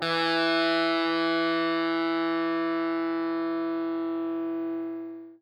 SPOOKY    AH.wav